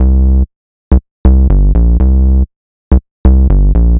TSNRG2 Bassline 019.wav